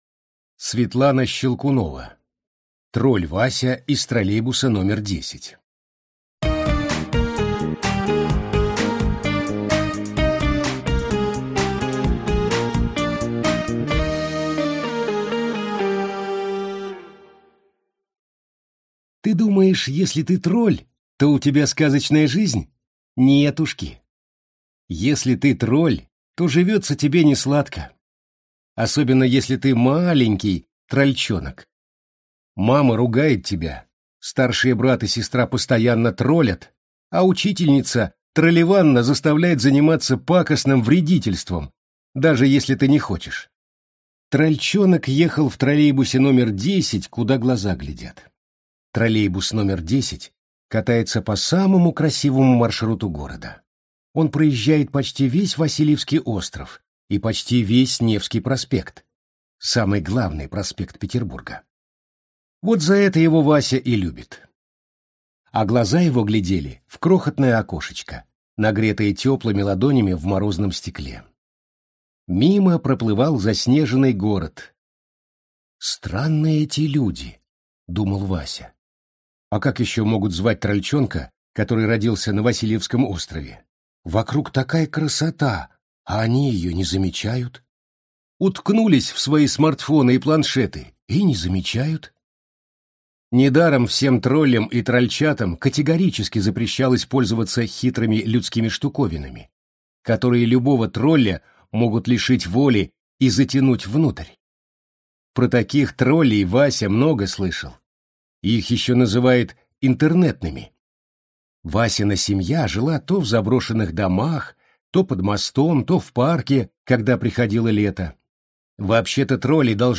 Аудиокнига Тролль Вася из троллейбуса № 10 | Библиотека аудиокниг